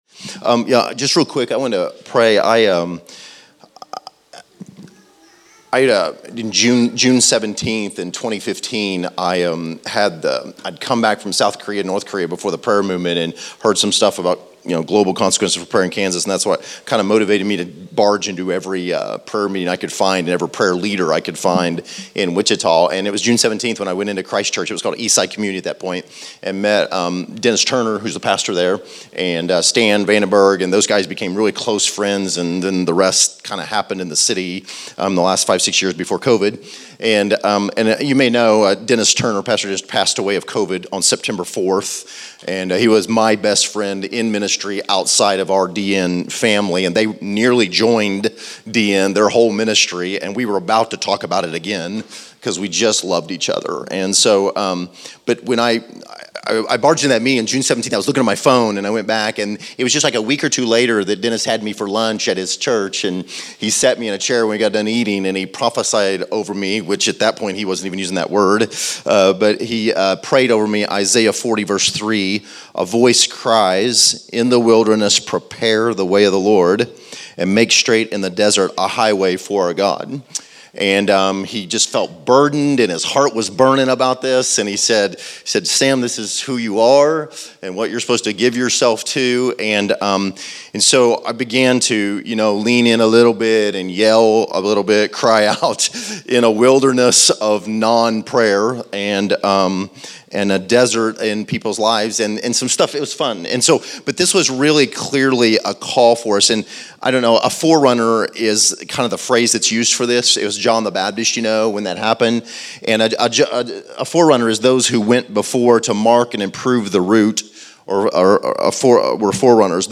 Category: Encouragements